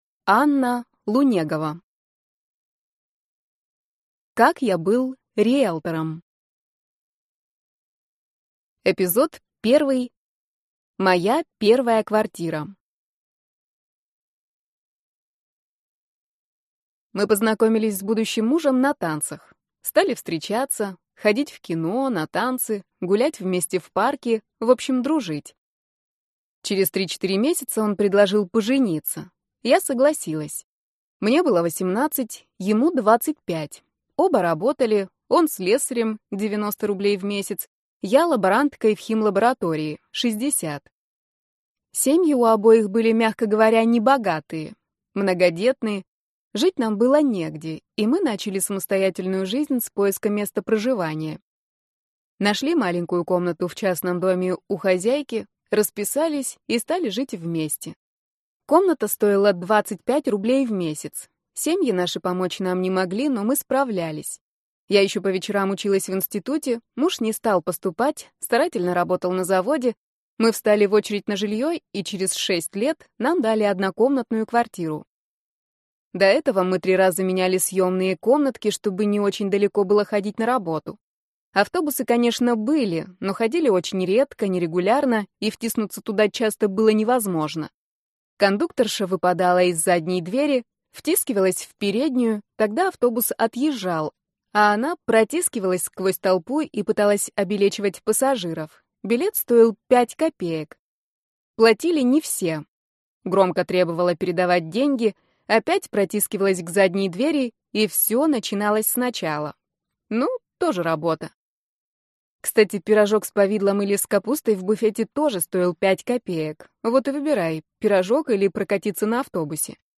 Аудиокнига Как я был риелтором | Библиотека аудиокниг